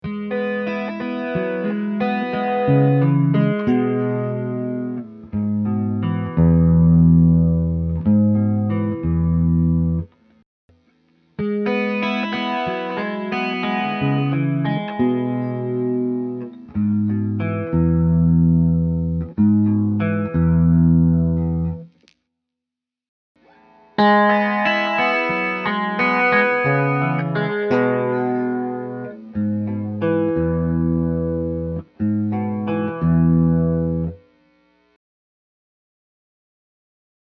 I’ve included some mp3’s of various tones recorded with a POD x3 straight into my laptop.  The tracks have been kept as raw as possible – no post EQ or sweetening – to give you a sense of what the guitar sounds like when you plug it in.
First just some simple solo guitar sounds, I used a clean Hiwatt model with a little verb
here are some simple fingerpicking sounds.  There are 3 samples – recorded in order of neck, middle pickup selector position (out of phase) and bridge positions.
ultrasonic-demo-fingerpick.mp3